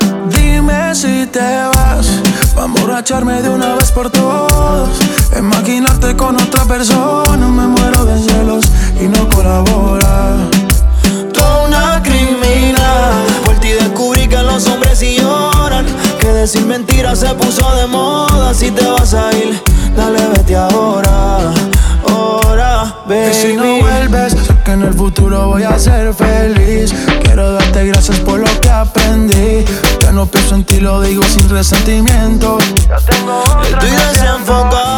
Скачать припев
Latin